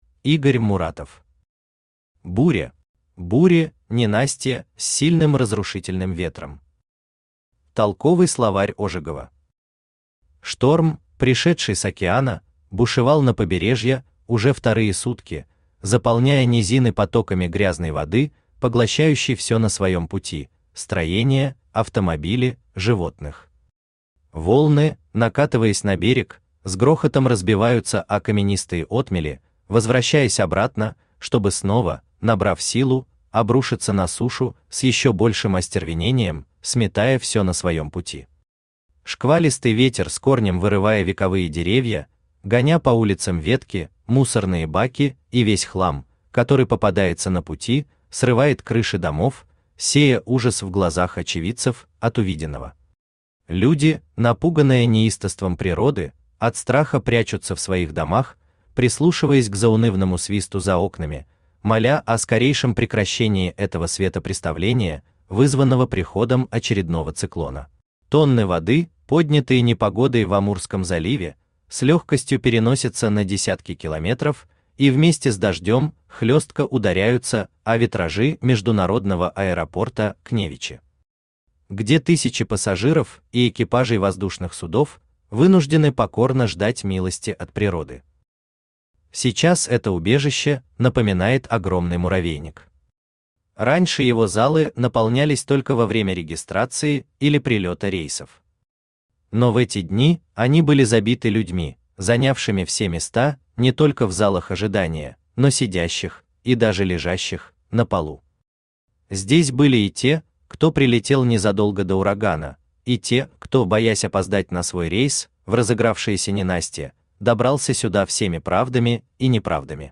Аудиокнига Буря | Библиотека аудиокниг
Aудиокнига Буря Автор Игорь Муратов Читает аудиокнигу Авточтец ЛитРес.